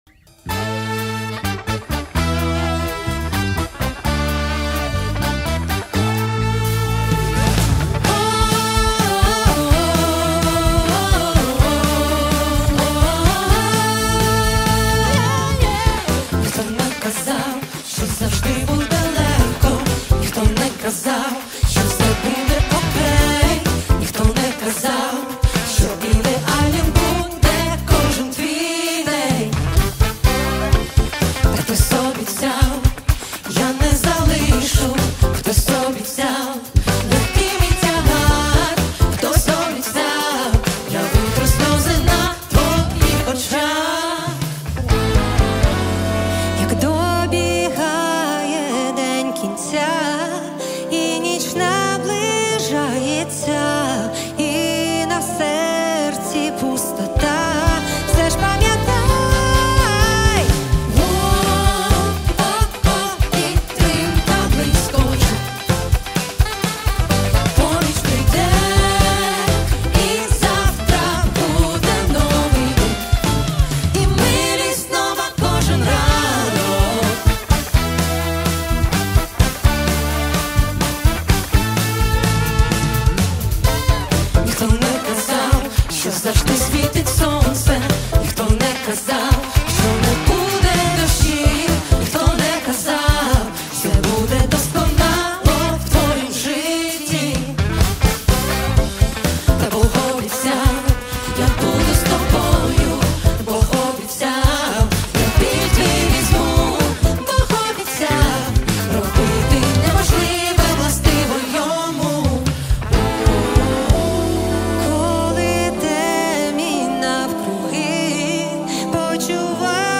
84 просмотра 79 прослушиваний 4 скачивания BPM: 128